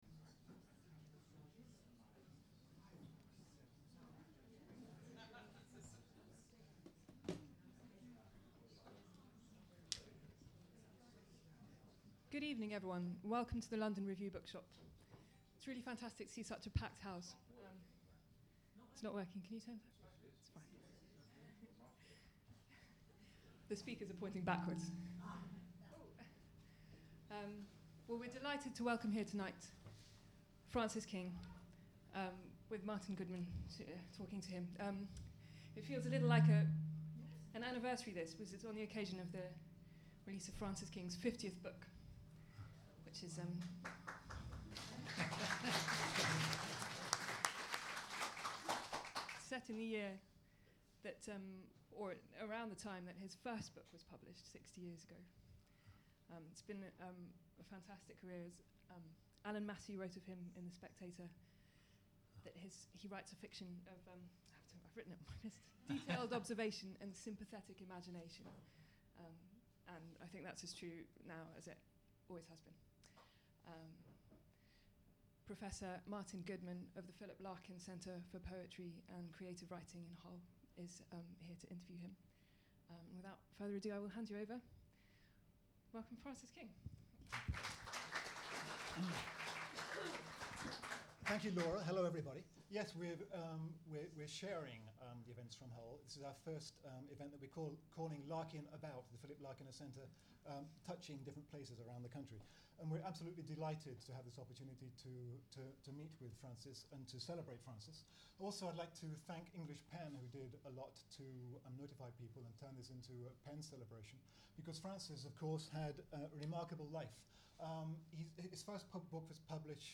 A celebration of the novelist Francis King and his new novel Cold Snap. Recording of an event held 21st January 2010 at the London Review Bookshop.